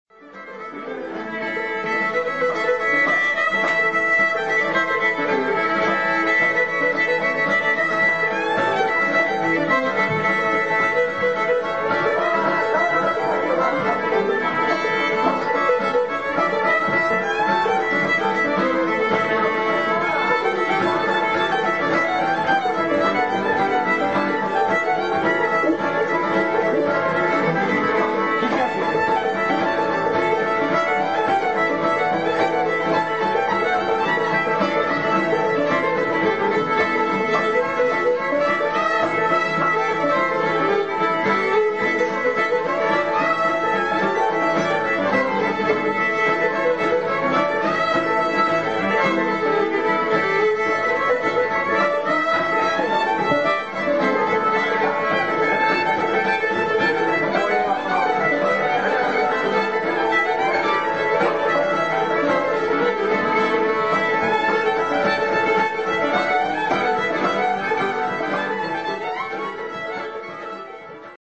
Fiddle
Guitar